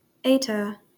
Eta (/ˈtə, ˈtə/